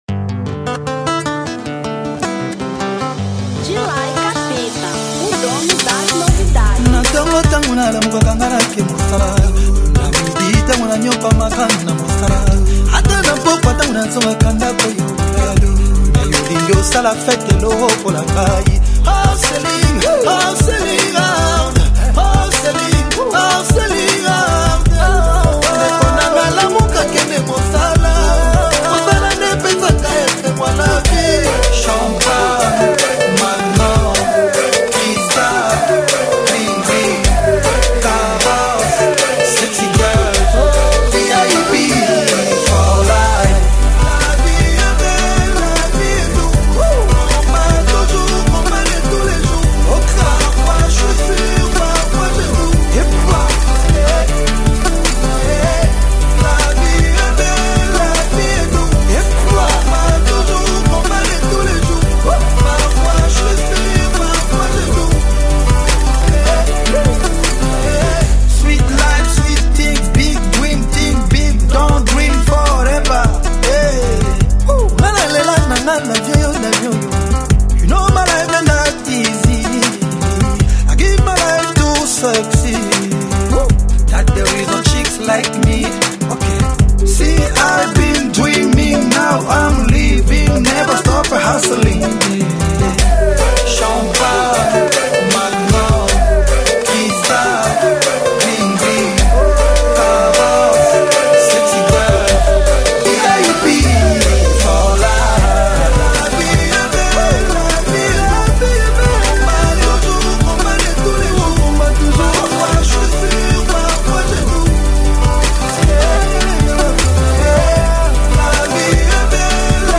Afro Beat 2013